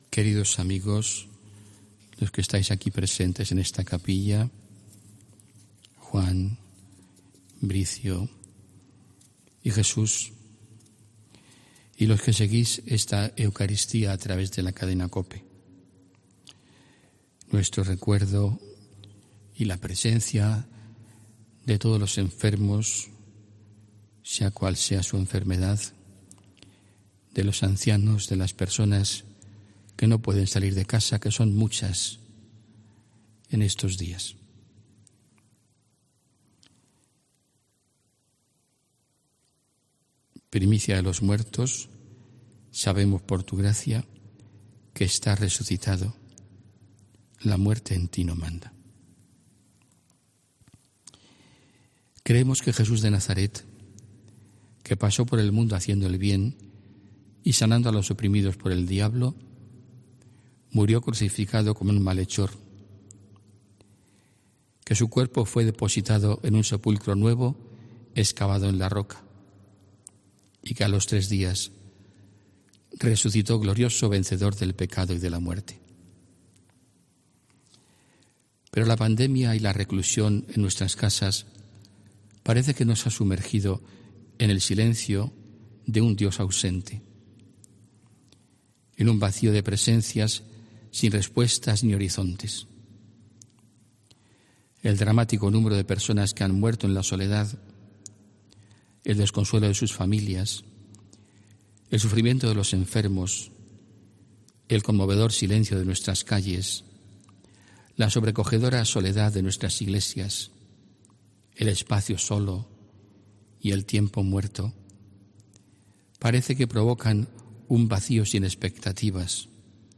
HOMILÍA 12 ABRIL 2020